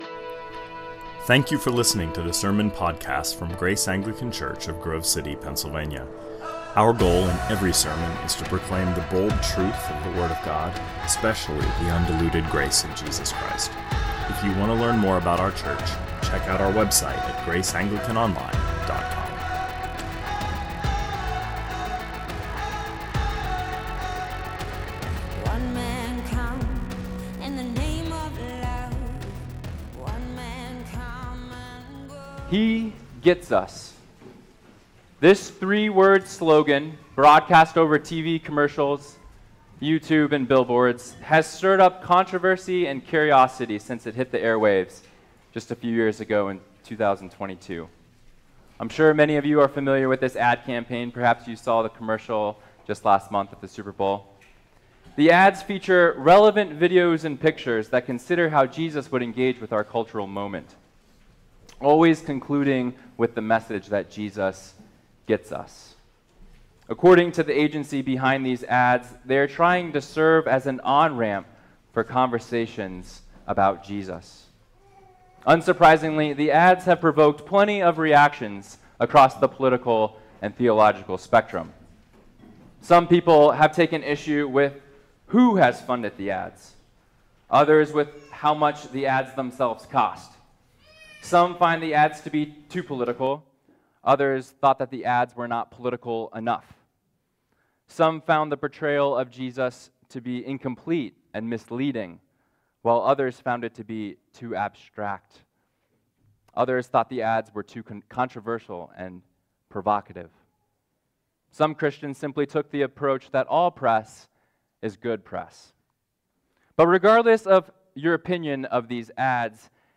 2025 Sermons